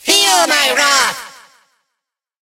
evil_mortis_ulti_vo_02.ogg